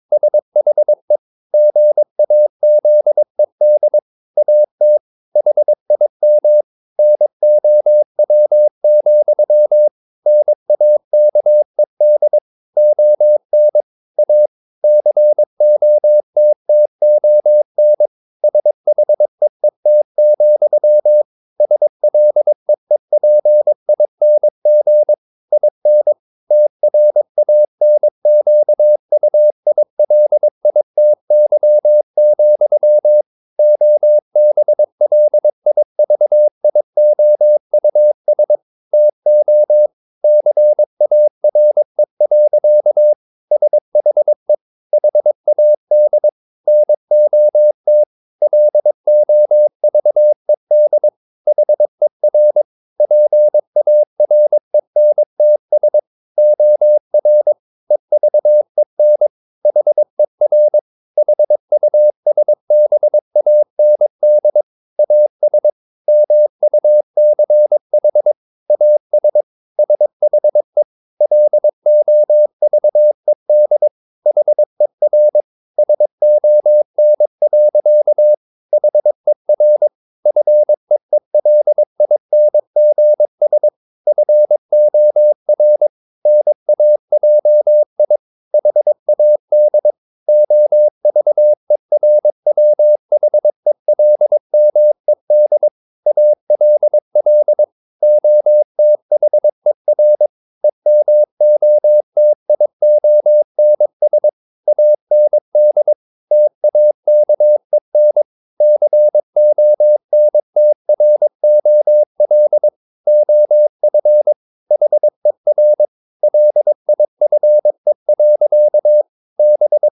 Never 22wpm | CW med Gnister